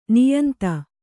♪ niyanta